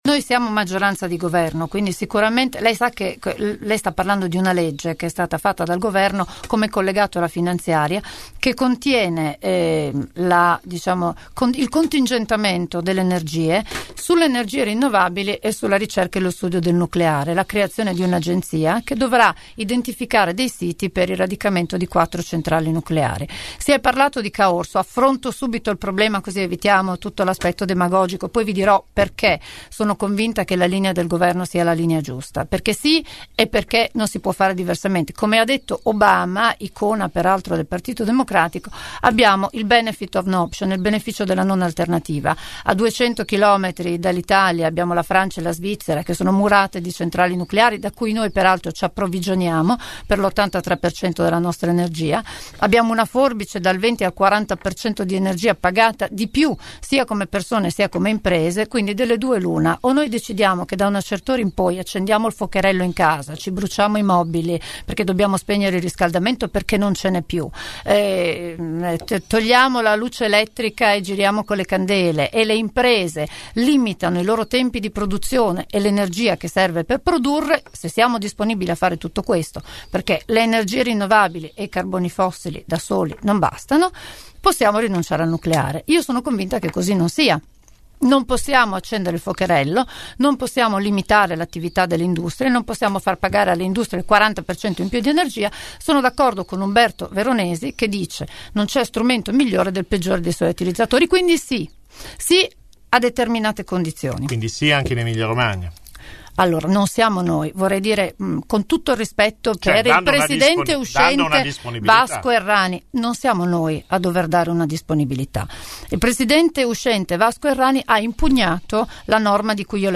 Ecco una sintesi dell’intervista andata in onda all’interno di Angolo B.